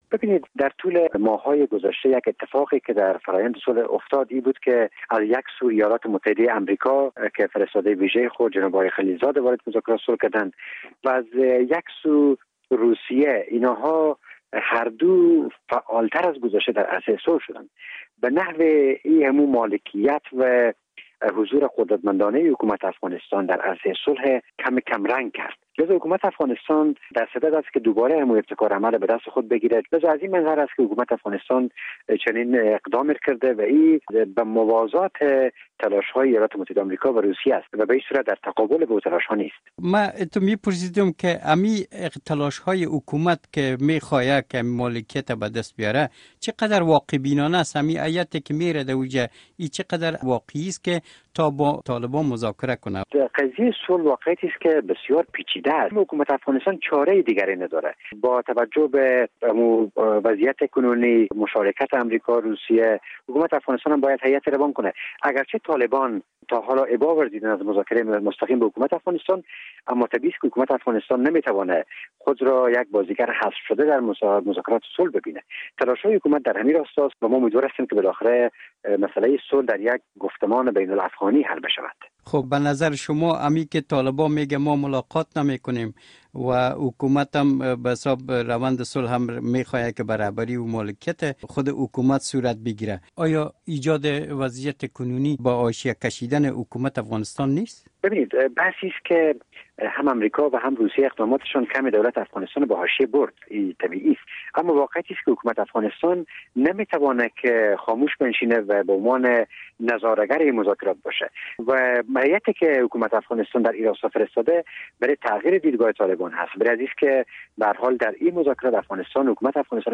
مصاحبه - صدا